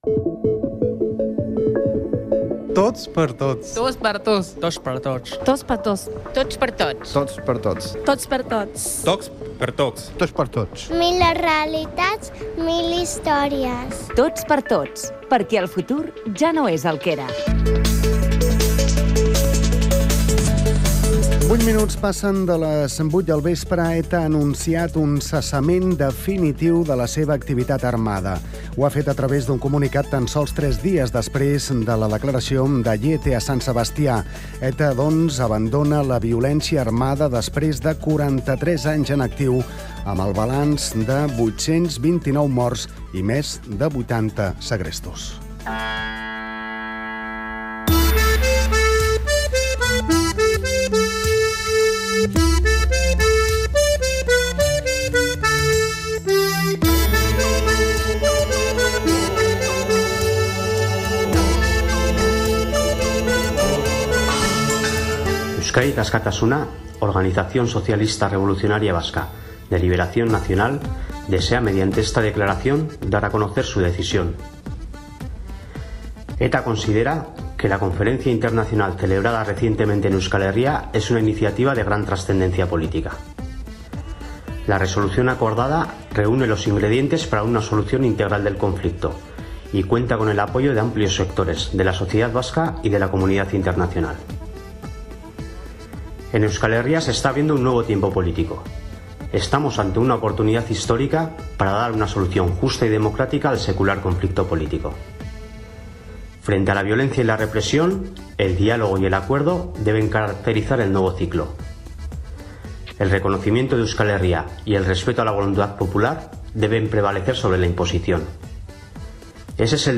f2144ac8fa8ccd48b41e254ec29abe3d9a360331.mp3 Títol COM Ràdio Emissora COM Ràdio Barcelona Cadena COM Ràdio Titularitat Pública nacional Nom programa Tots per tots Descripció Fragment d'un especial arran del cessament definitiu de l'activitat armada de la organització terrorista d'ETA. Comunicat de la banda ETA. Taula d'anàlisi